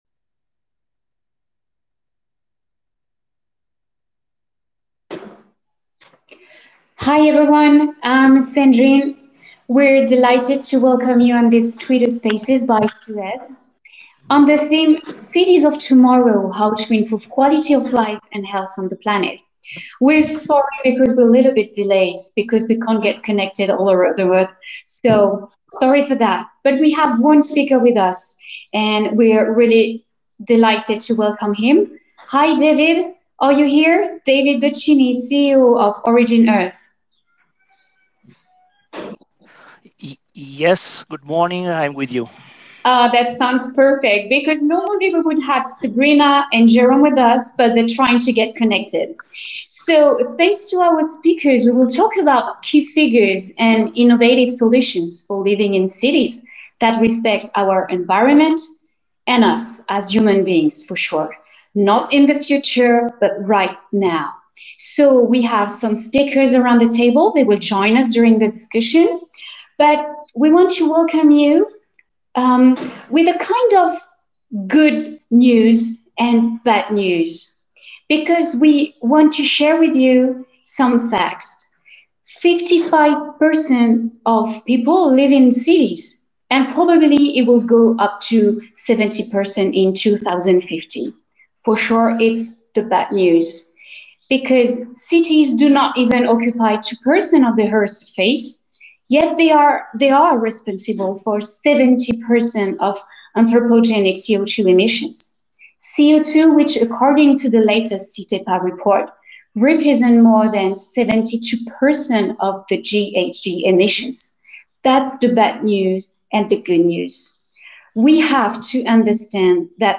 ont participé à un Twitter Spaces sur la Ville de demain